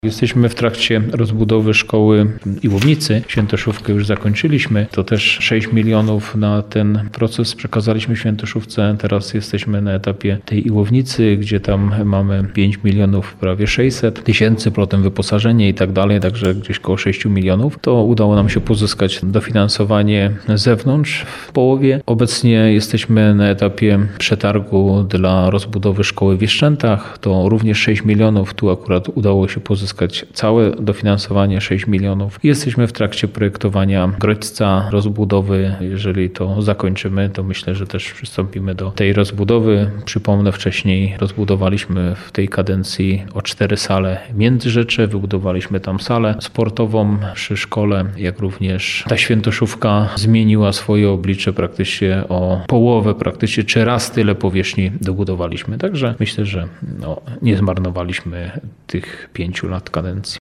O powiększaniu placówek rozmawialiśmy niedawno na naszej antenie z wójtem gminy Januszem Pierzyną.